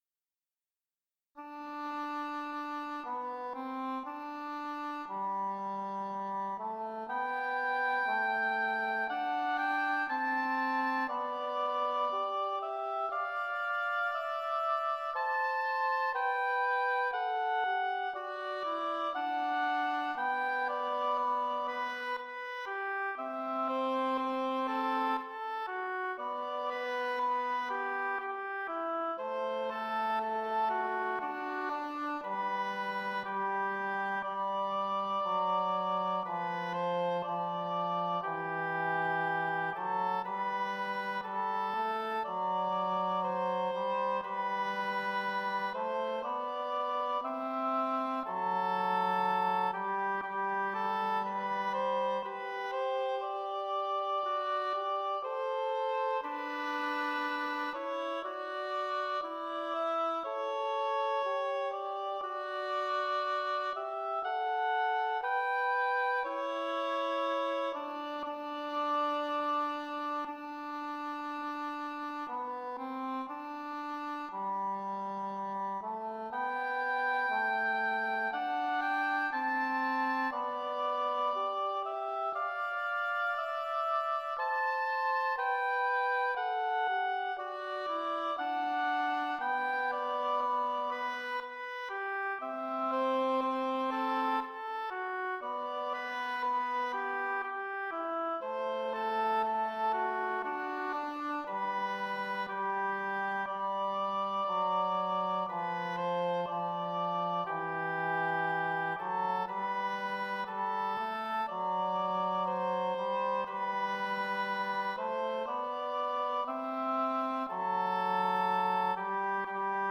Intermediate oboe & english horn duet
Instrumentation: oboe & english horn duet
tags: oboe music, english horn music